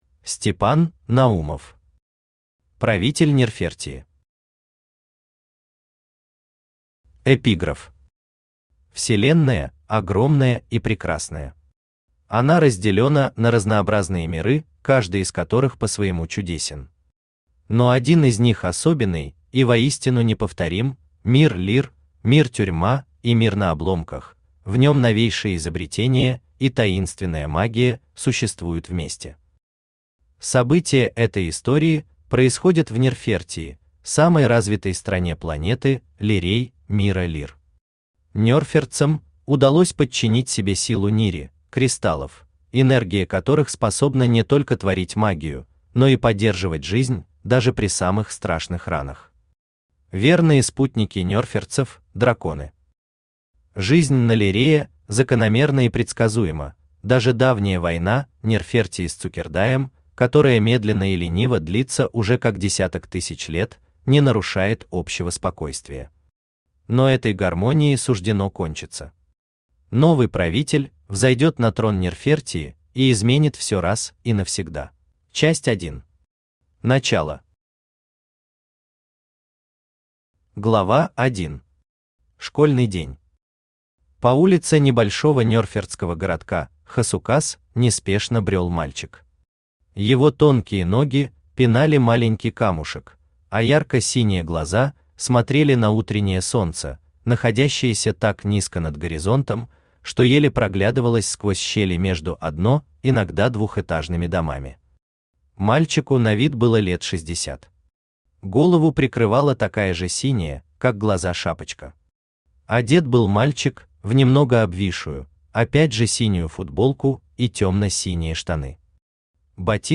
Аудиокнига Правитель Нерфертии | Библиотека аудиокниг
Aудиокнига Правитель Нерфертии Автор Степан Наумов Читает аудиокнигу Авточтец ЛитРес.